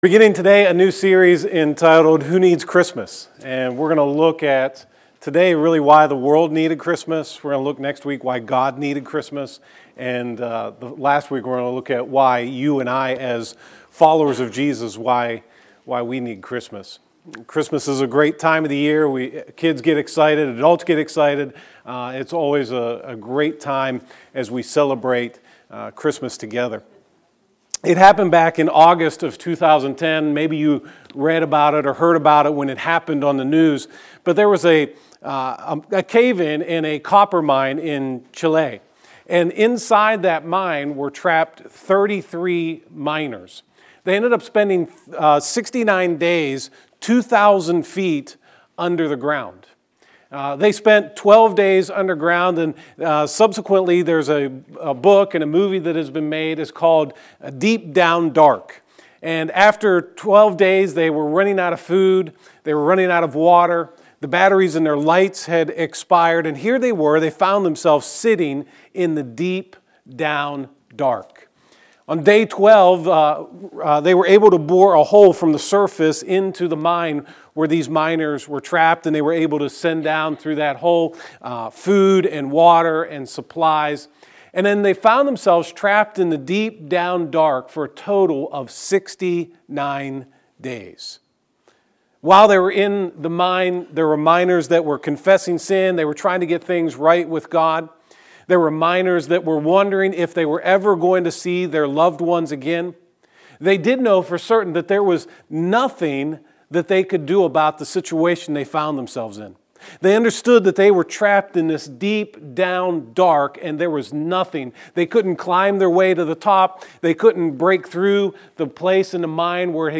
The World Does Preacher